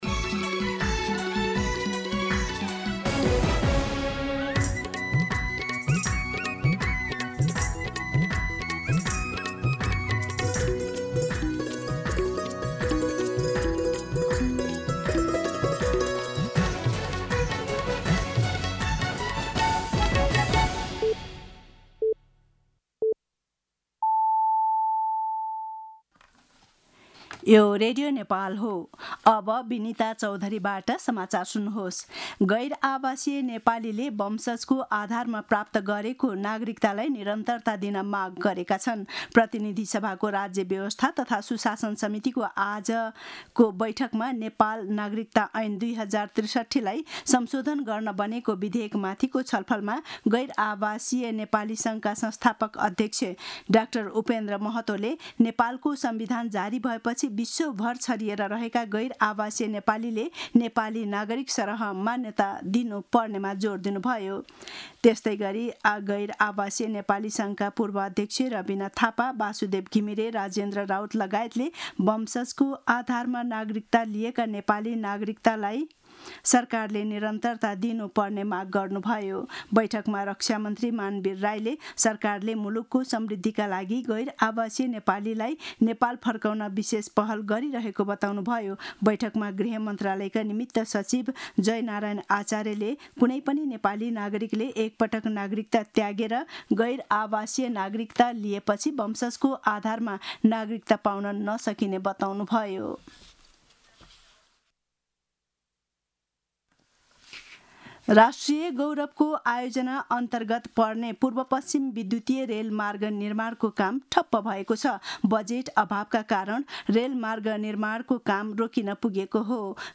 दिउँसो १ बजेको नेपाली समाचार : २६ जेठ , २०८२